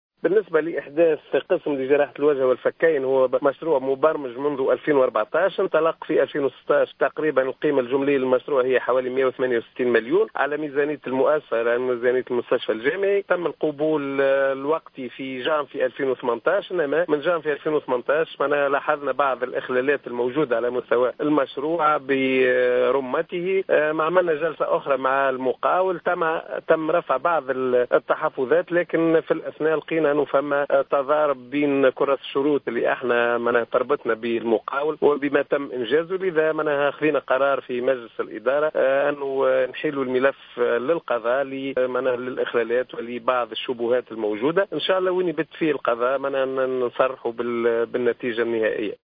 وأشار الهواني في تصريح للجوهرة أف أم أن الكلفة الجملية للمشروع تبلغ 168 الف دينار.